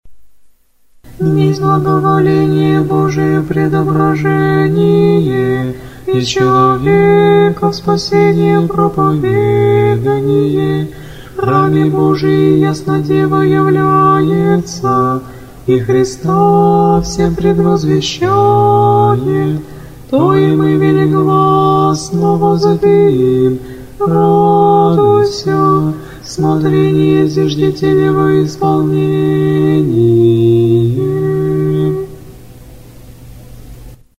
Тропарь Введения во храм Пресвятой Богородицы